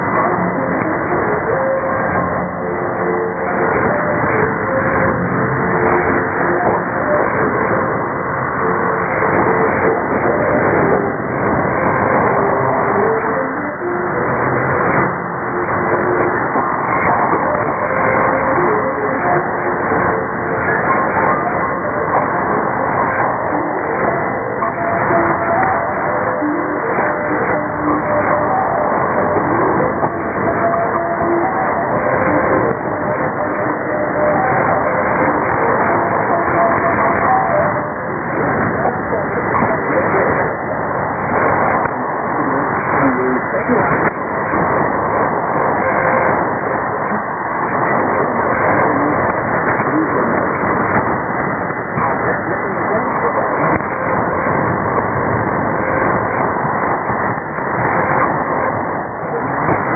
・このＨＰに載ってい音声(ＩＳとＩＤ等)は、当家(POST No. 488-xxxx)愛知県尾張旭市で受信した物です。
IS: interval signal